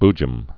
(bjəm)